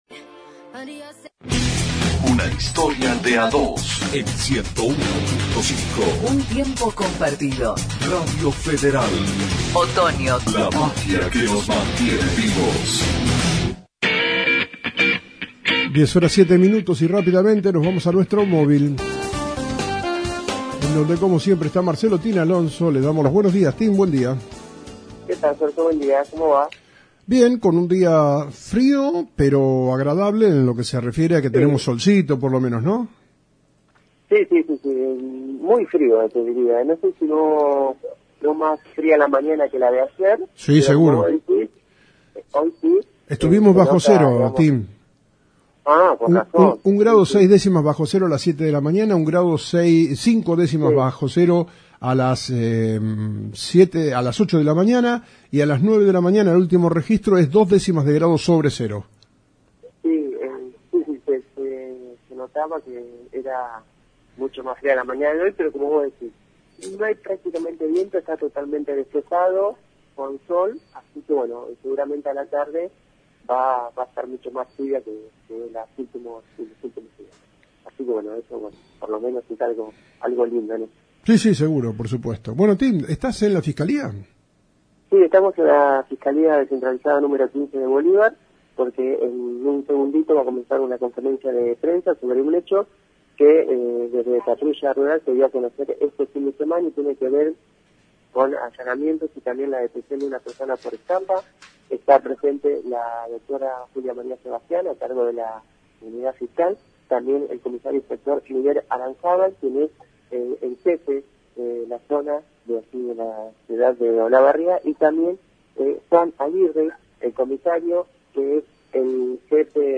Conferencia de Prensa de la Fiscalía de Bolívar